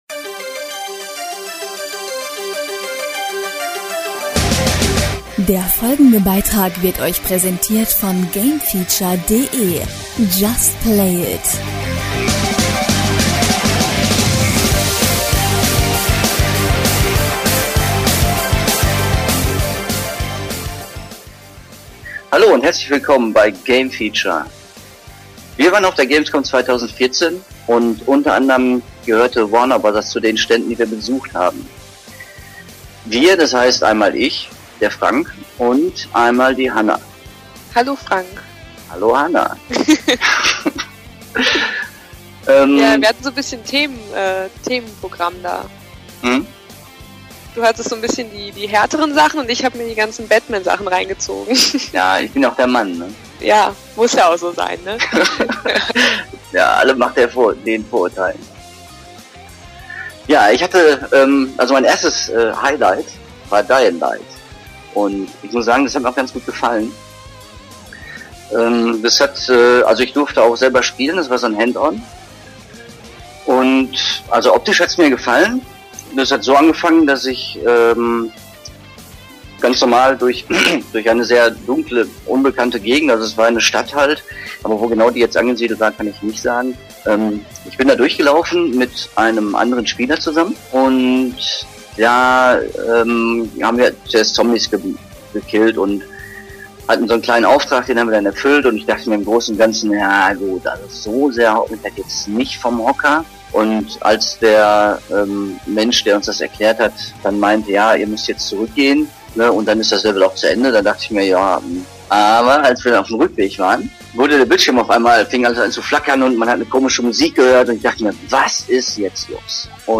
(sorry für die etwas schlechtere Soundqualität) Auf der gamescom 2014 in Köln zeigte Warner Bros.